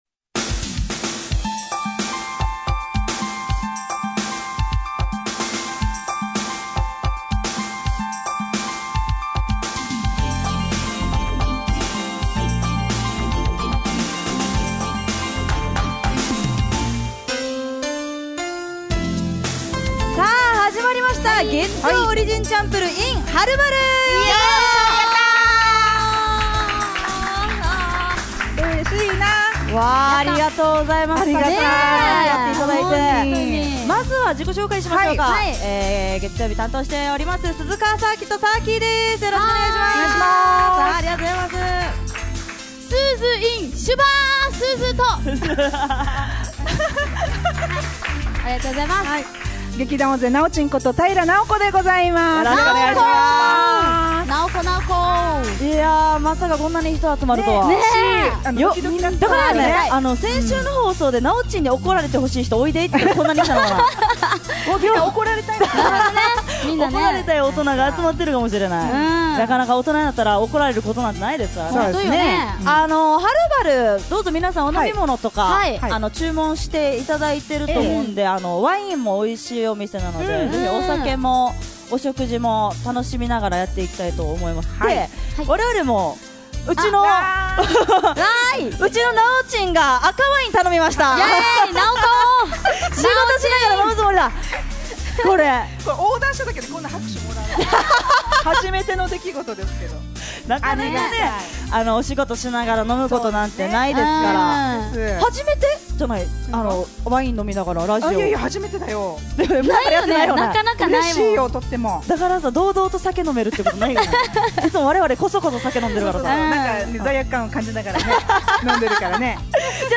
160418月曜オリジンちゃんぷるー公開収録 in ハル晴ル